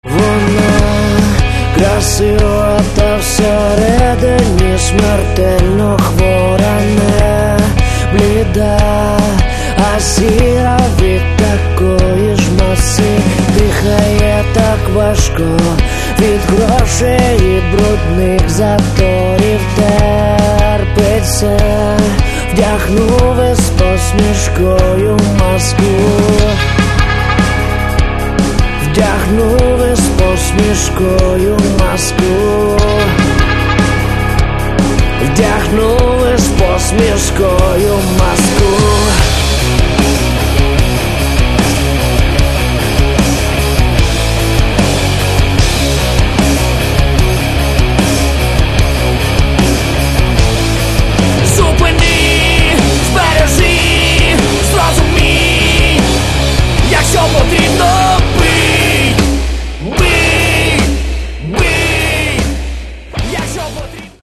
Каталог -> Рок та альтернатива -> Просто рок